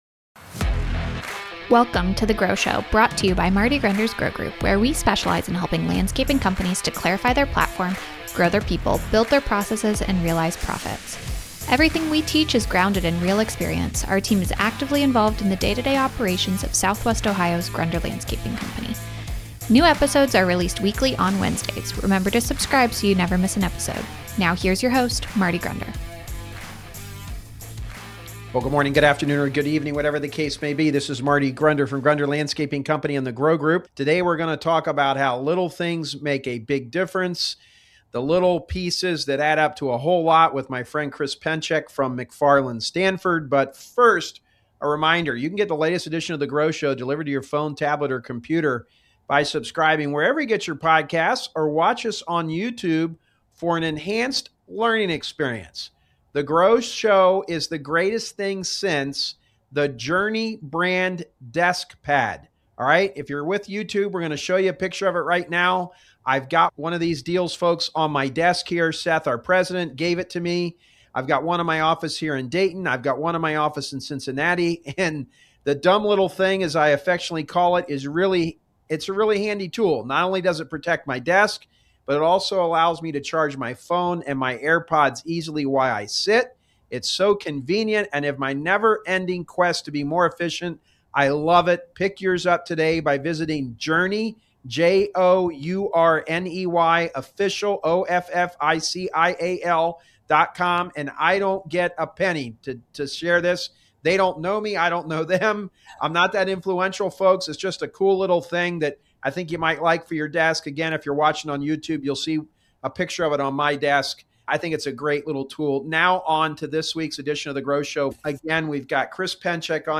Drawing from Captain Michael Abrashoff's leadership principles in "It's Your Ship," they break down practical applications across the Four P Framework: Platform, People, Process, and Profits. The conversation emphasizes that success in the landscape industry comes from mastering the details that most people overlook.